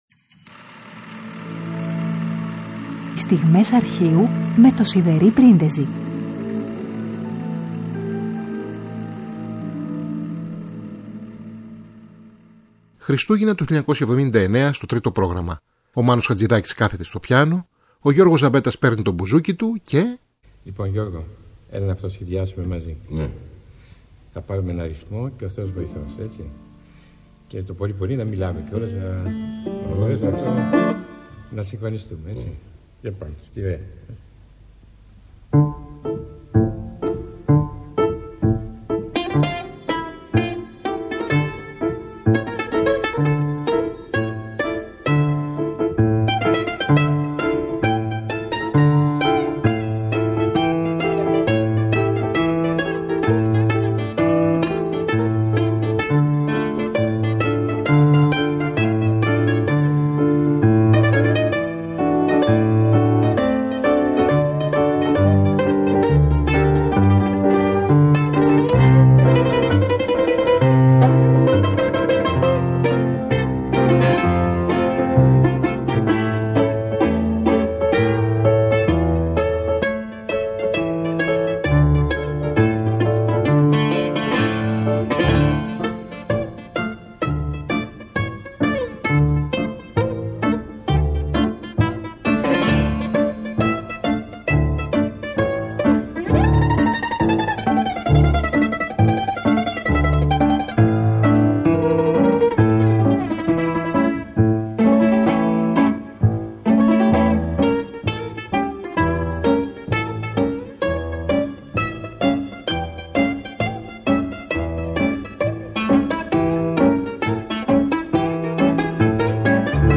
πιάνο
μπουζούκι